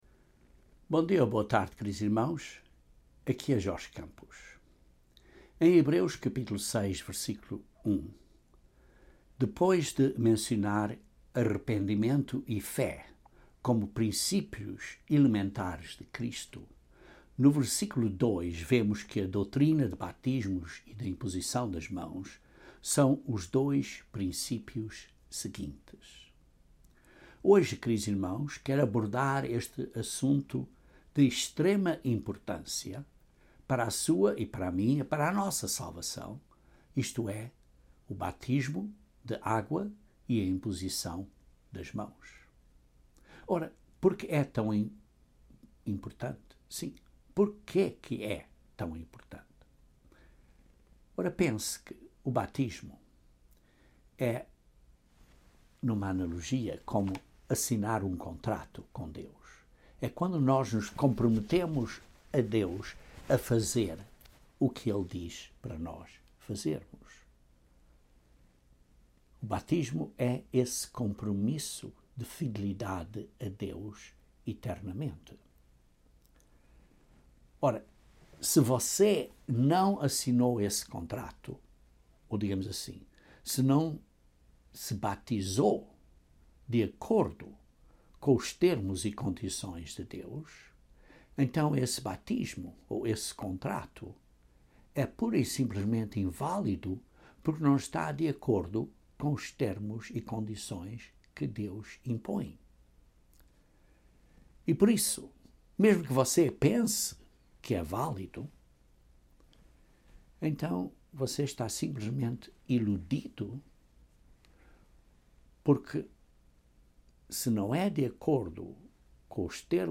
Este sermão descreve a importância de sermos batizados em Cristo e o que isso significa, além de sermos batizados em nome de Cristo.